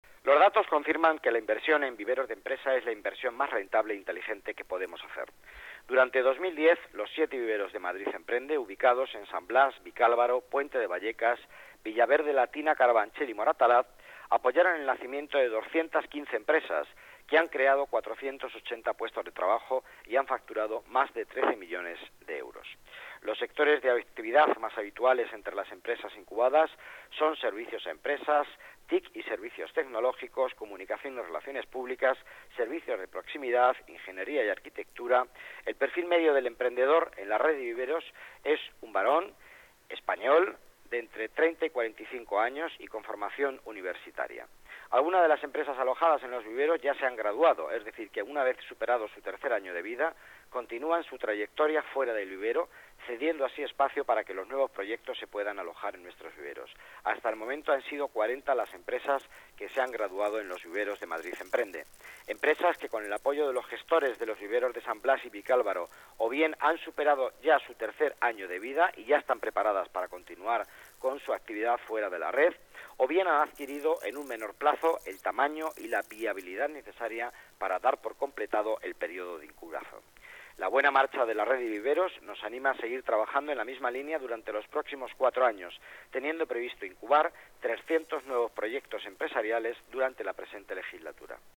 Nueva ventana:Declaraciones del delegado de Economía, Empleo y Participación Ciudadana, Miguel Ángel Villanueva